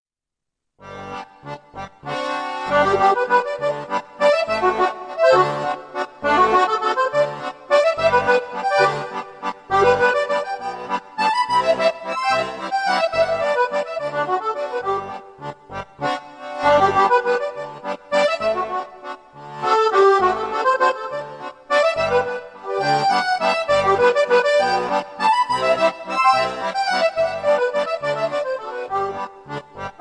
a lively varied waltz